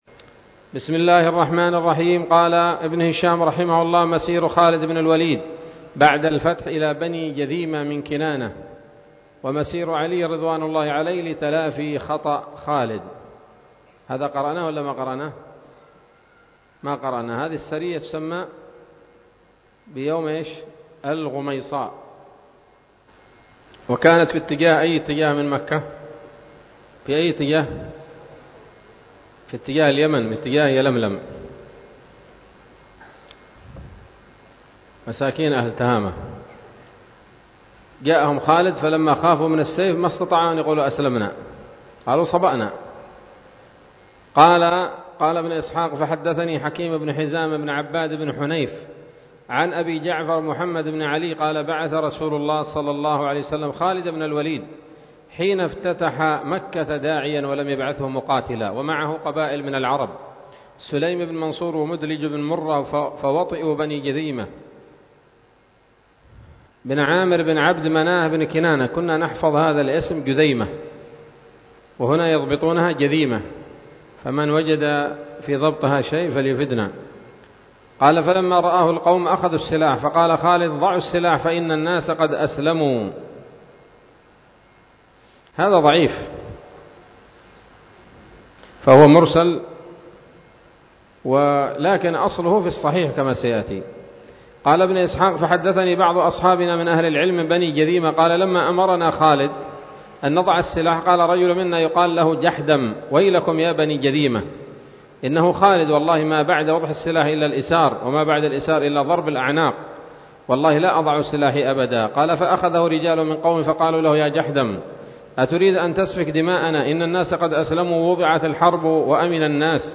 الدرس التاسع والستون بعد المائتين من التعليق على كتاب السيرة النبوية لابن هشام